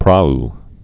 (prä)